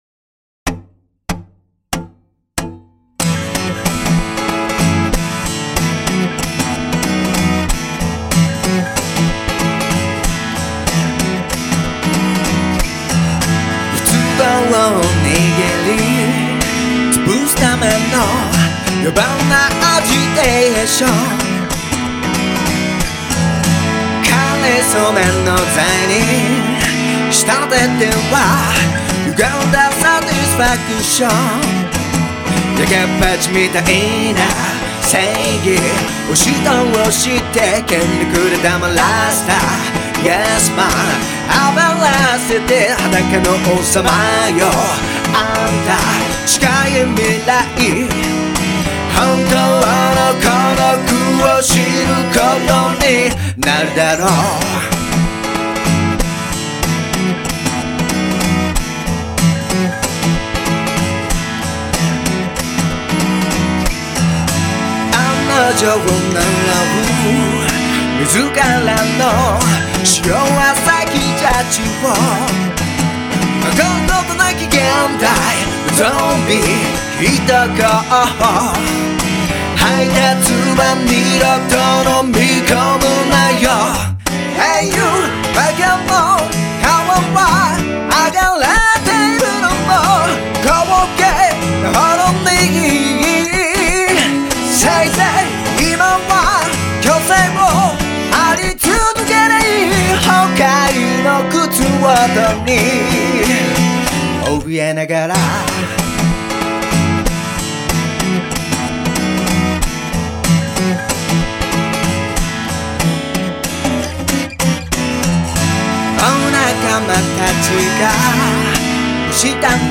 だってロックは衝動だから。